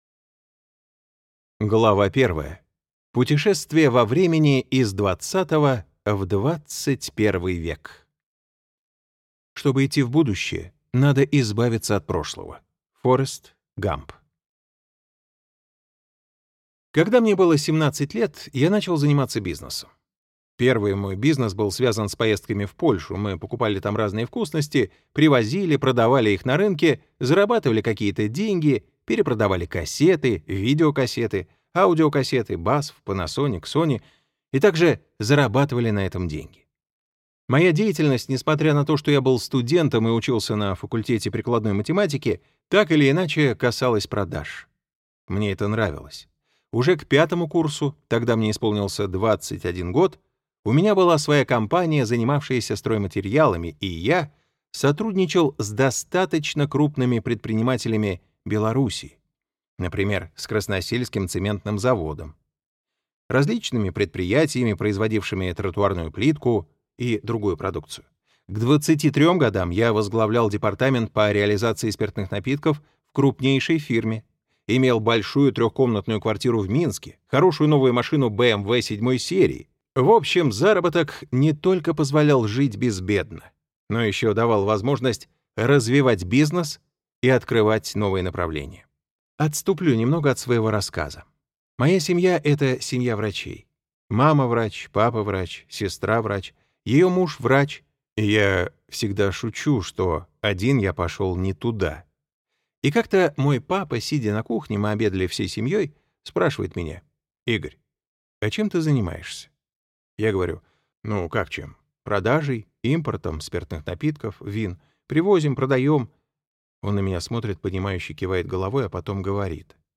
Аудиокнига Монстр продаж. Как чертовски хорошо продавать и богатеть | Библиотека аудиокниг